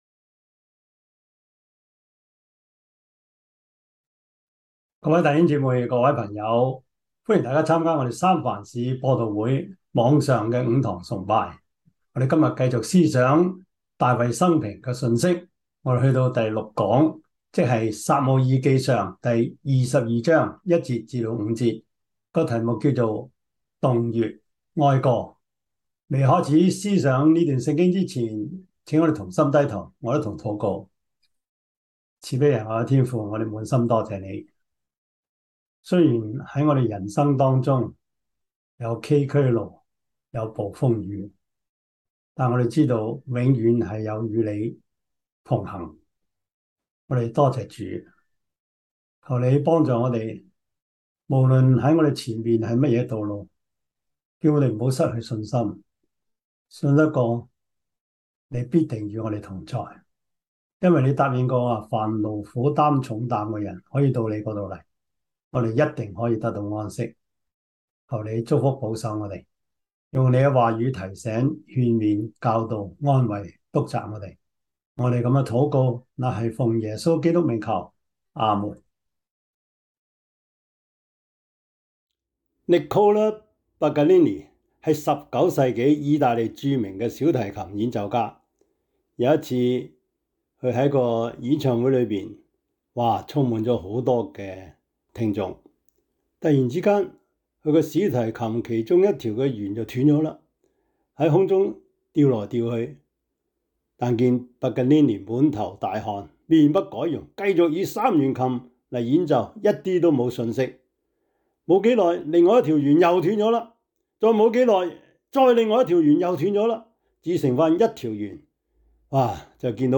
撒母耳記上 22:1-5 Service Type: 主日崇拜 撒 母 耳 記 上 22:1-5 Chinese Union Version